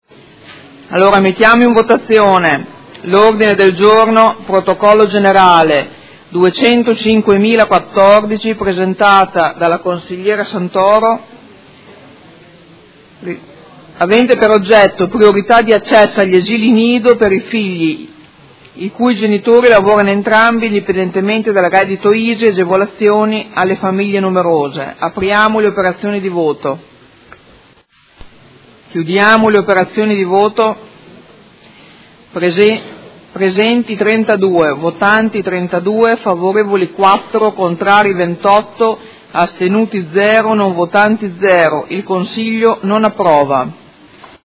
Seduta del 20/12/2018. Mette ai voti Ordine del Giorno Prot. Gen. 205014